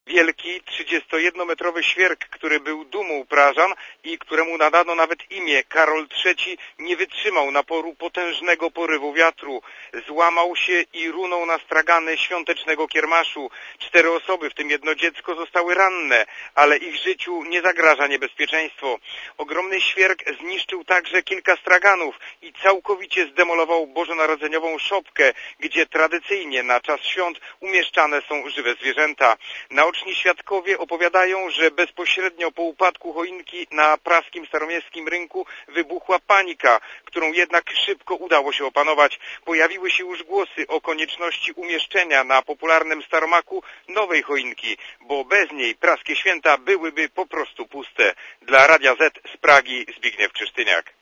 Korespondencja z Pragi (200Kb)